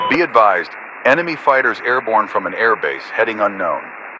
Radio-commandNewEnemyAircraft1.ogg